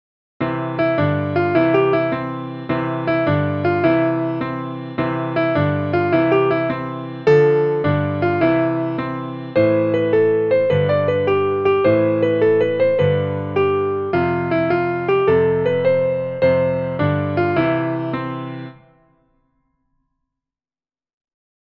traditional Nursery Rhyme and Song
for piano